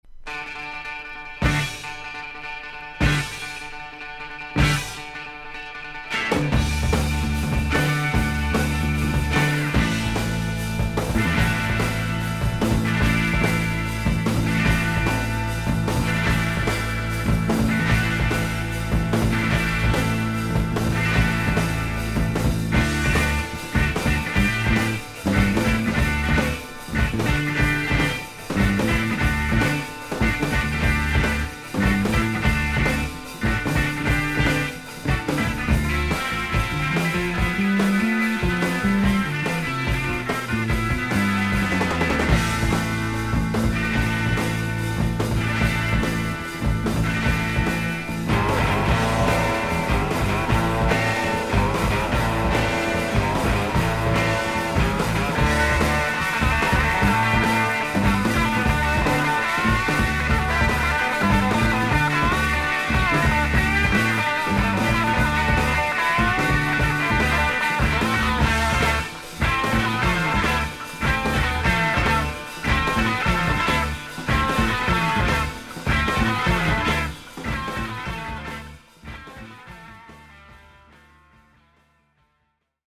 少々軽いパチノイズの箇所あり。クリアな音です。
B面は少々薄いサーフィス・ノイズの箇所あり。